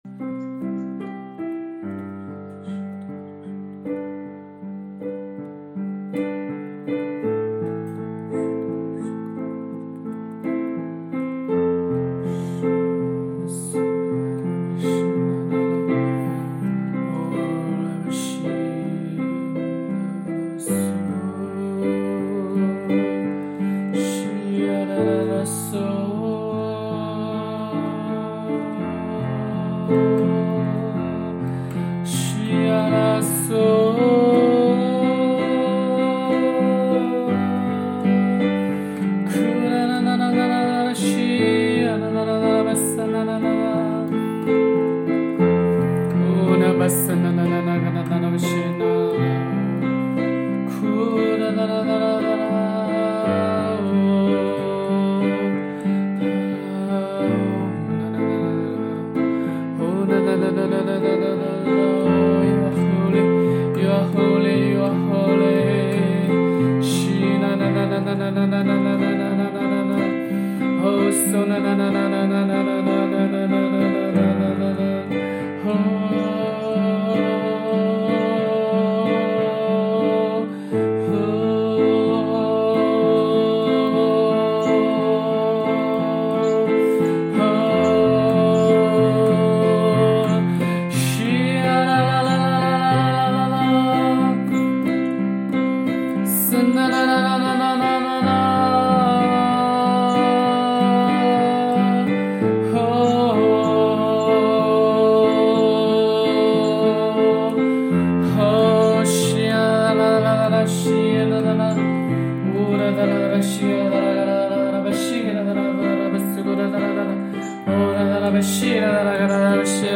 新季节 HAKA祷告 第44天 敬拜独一真神